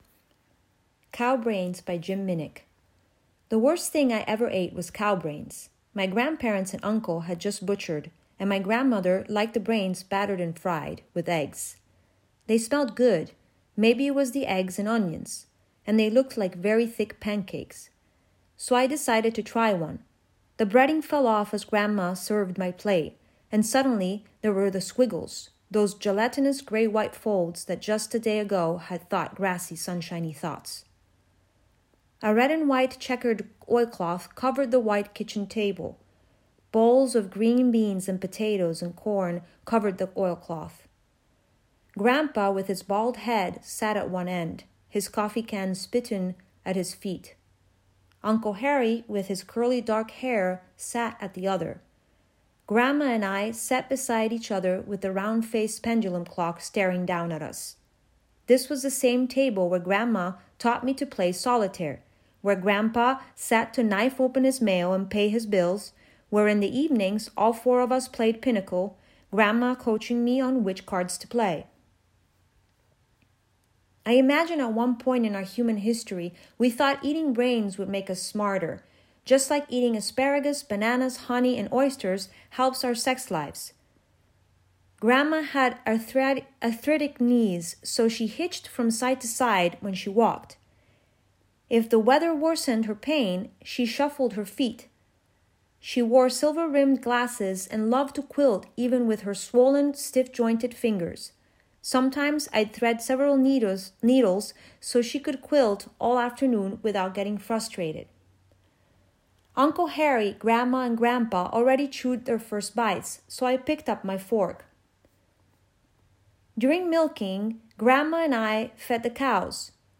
readings from our Online Podcast: